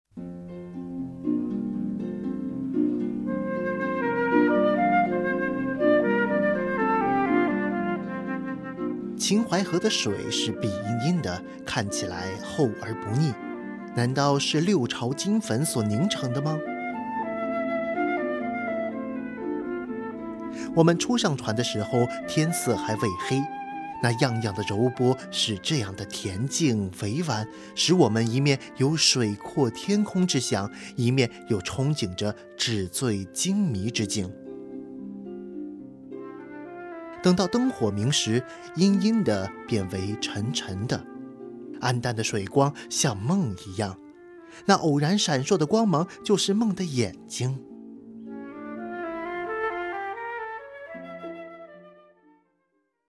chinesischer Profi-Sprecher. Vertonung von Industriefilmen und Werbefilmen
Sprechprobe: Industrie (Muttersprache):
chinese voice over artist